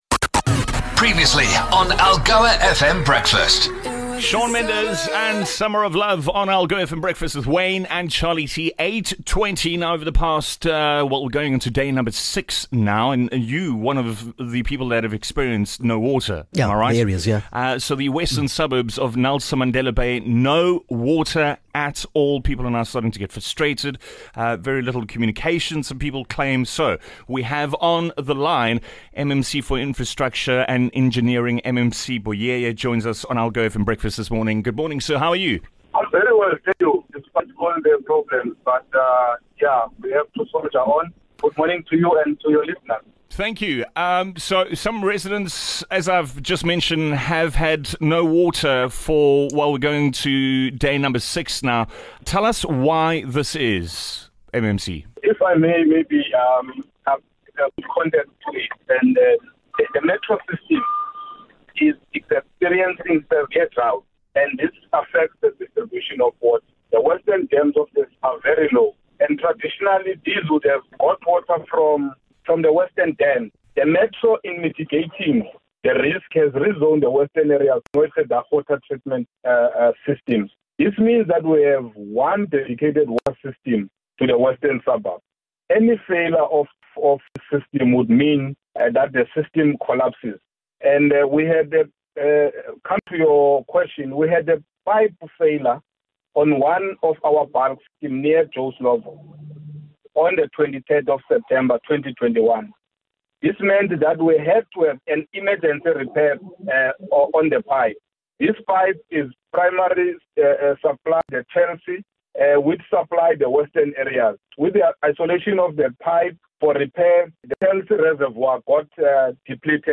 MMC for Infrastructure and Engineering, Cllr Thsonono Buyeye, was interviewed by the Breakfast Team as they sought answers to the ongoing water supply battle in the NMB metro. Is there an end in sight?